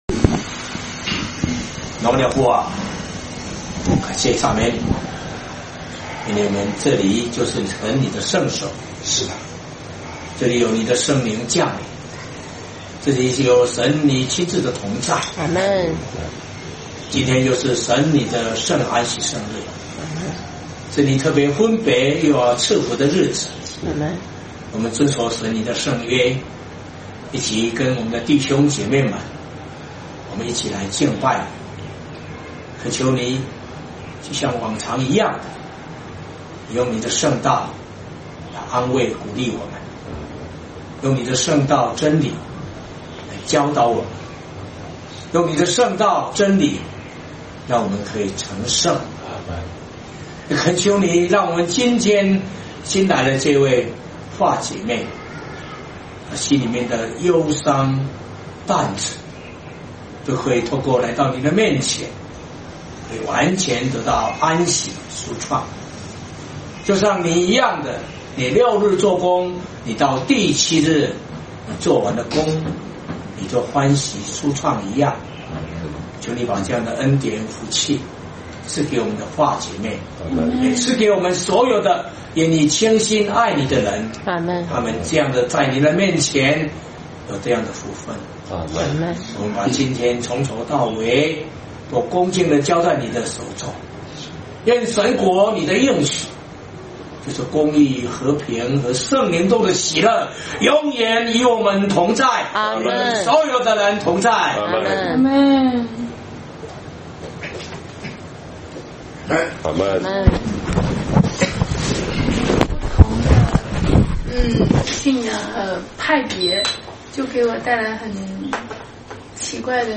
詩歌頌讚